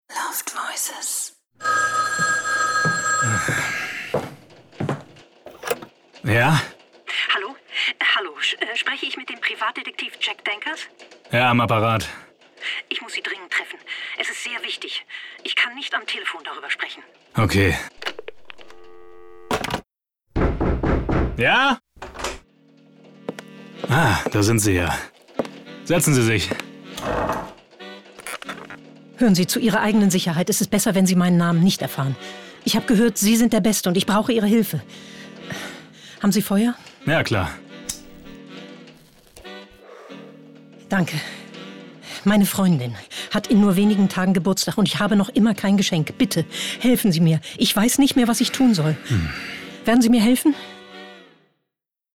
markant
Norddeutsch
Audio Drama (Hörspiel)